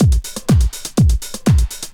OSH Track Master Beat 1_123.wav